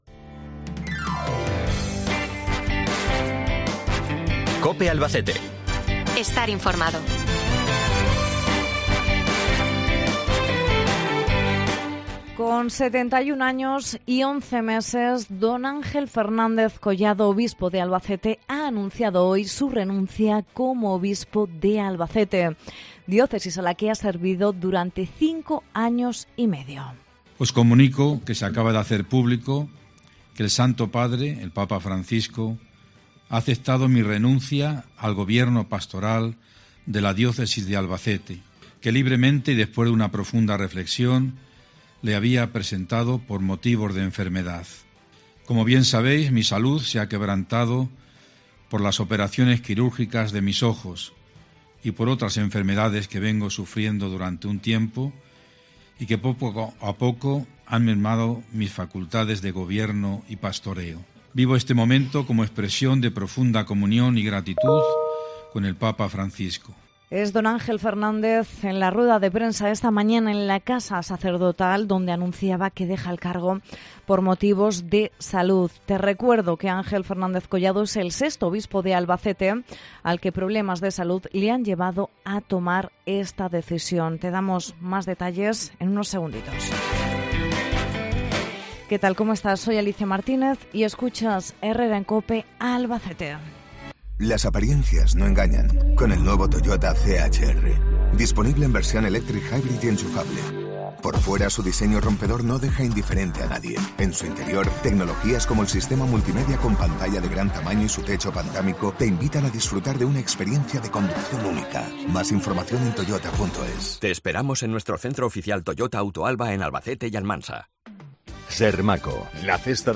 Mons. Fernández Collado así lo ha comunicado al mediodía de hoy en rueda de prensa en la Casa Sacerdotal y rodeado del clero albaceteño